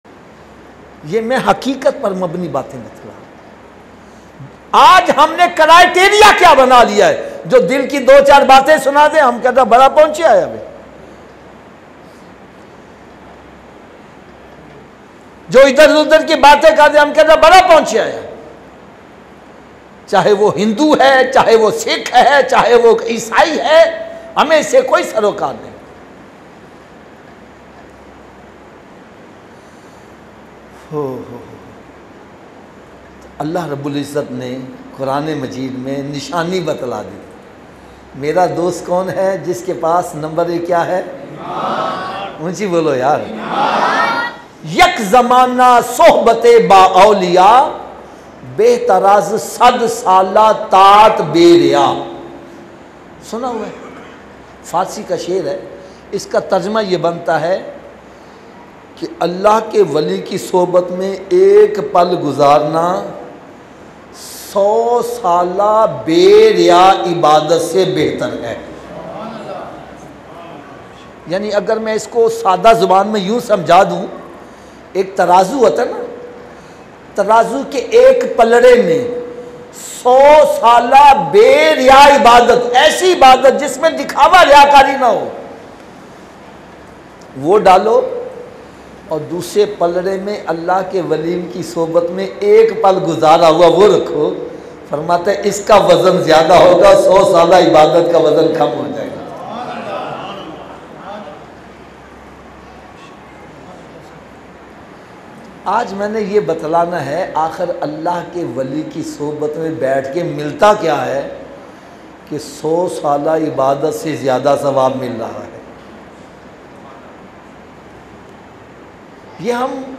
Allah Dekh Raha Hai Very Emotional Bayan MP3 Download